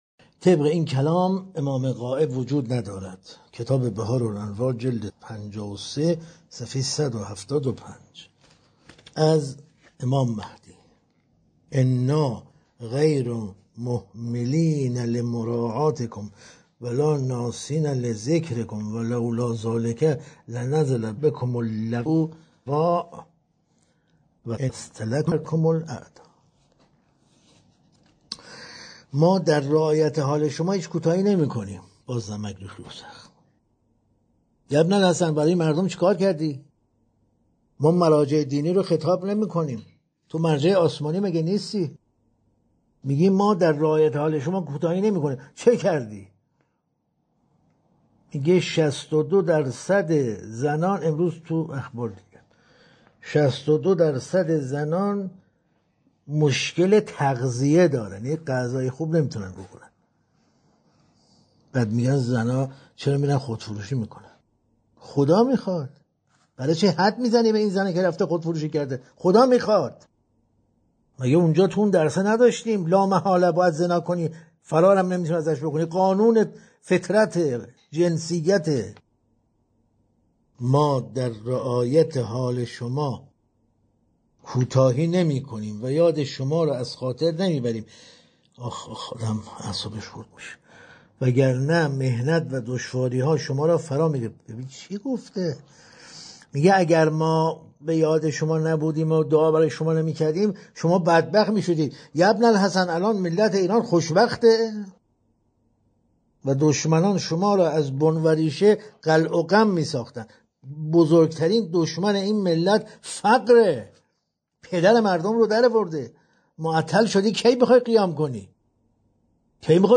در این بخش، می‌توانید گزیده‌ای از تدریس‌های روزانه بروجردی، کاشف توحید بدون مرز، را مطالعه کرده و فایل صوتی آن را بشنوید.